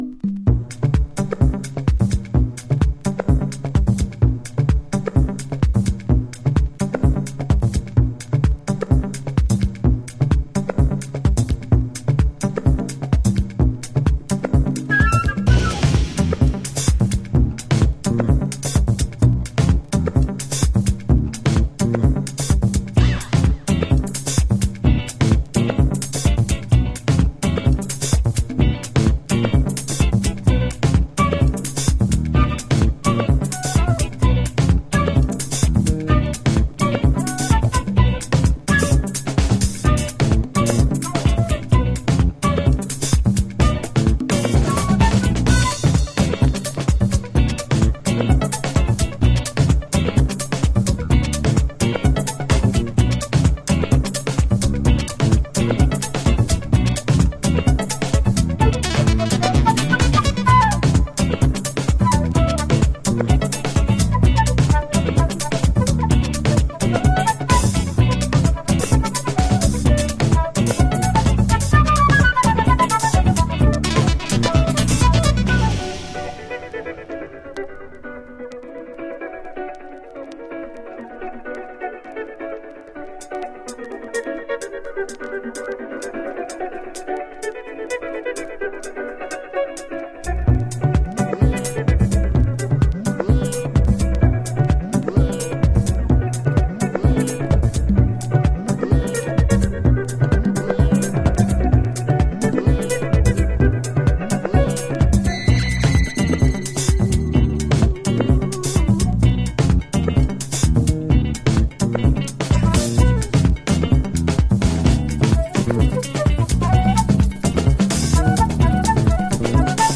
House / Techno
ねっとりドラムプログラミングにシンセとギターがエフェクティブに絡み合う、ラテンテイストのクリックハウス。